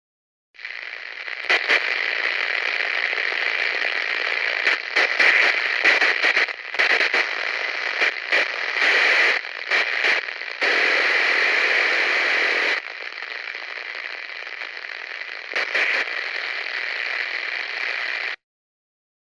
Звуки радио, радиопомех
Погрузитесь в атмосферу ретро-радио с коллекцией звуков помех, шипения и настройки частот.
Пропал радиосигнал